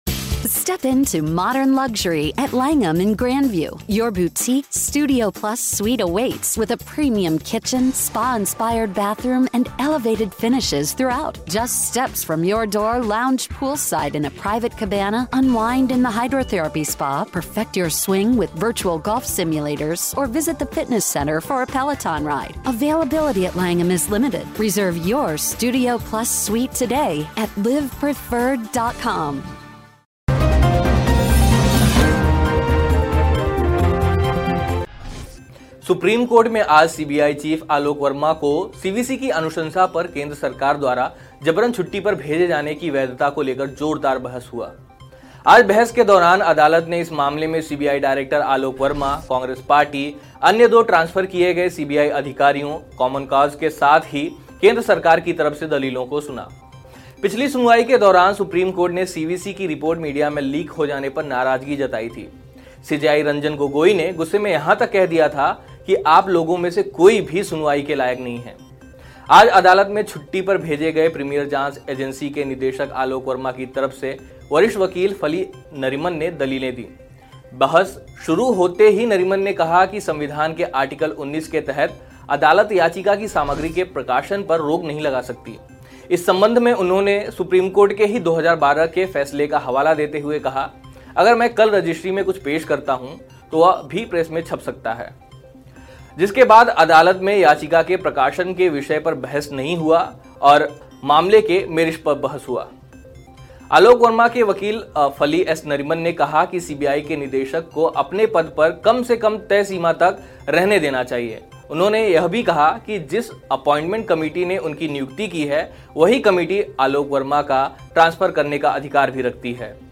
न्यूज़ रिपोर्ट - News Report Hindi / CBI vs CBI: सीबीआई चीफ को छुट्टी पर भेजने का अधिकार CVC को नहीं- कपिल सिब्बल